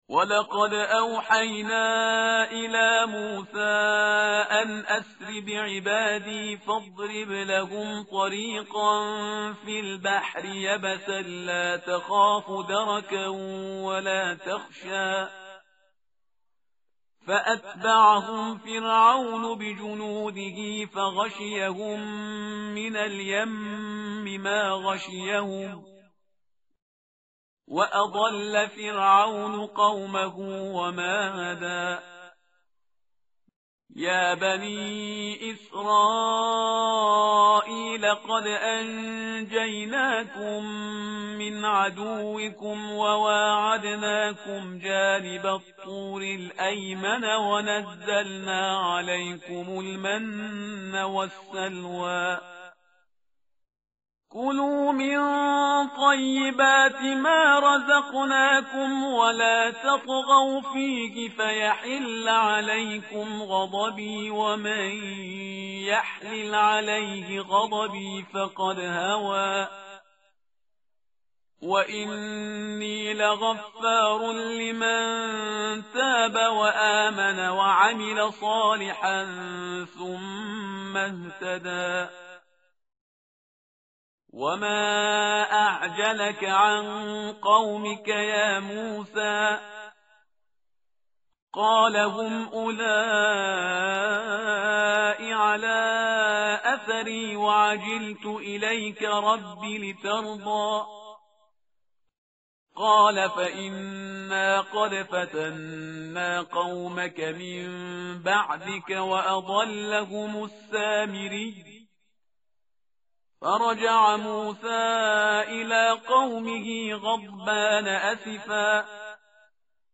متن قرآن همراه باتلاوت قرآن و ترجمه